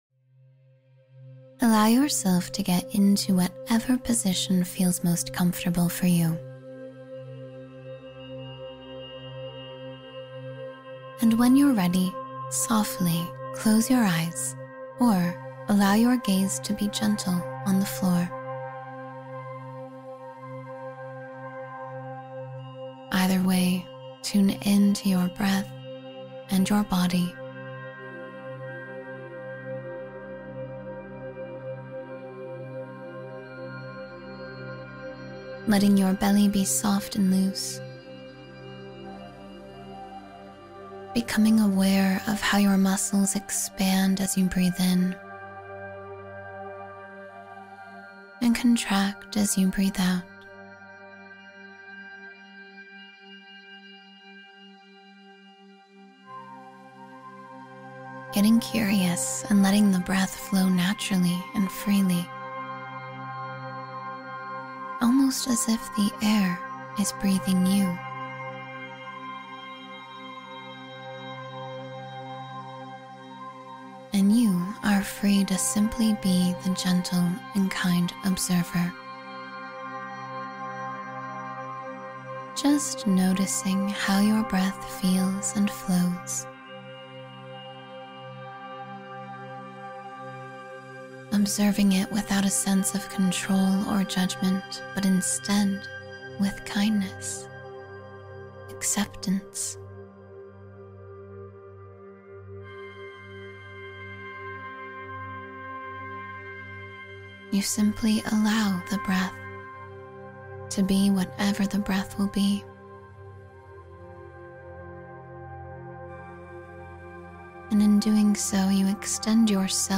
Clear Your Mind with Mindful Presence — Meditation for Mental Clarity and Calm